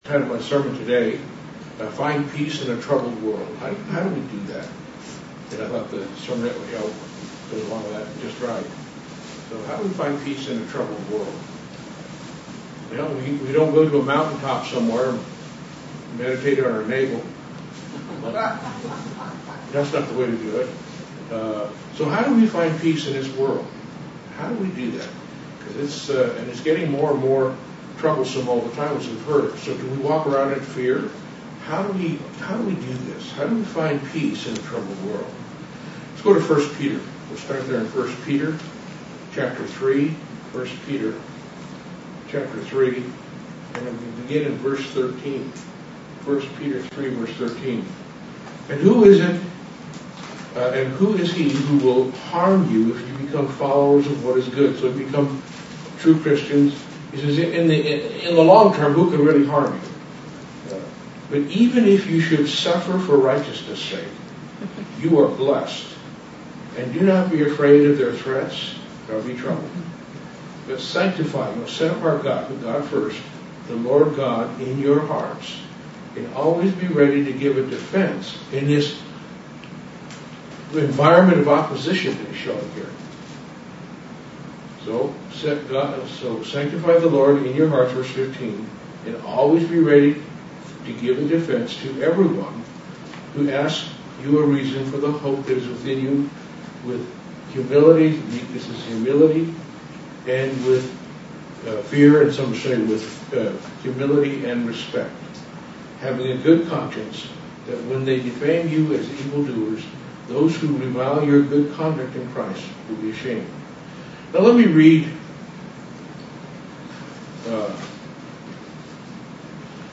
Sermons
Given in Dallas, TX Fort Worth, TX